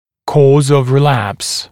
[kɔːz əv rɪ’læps][‘ко:з ов ри’лэпс]причина рецидива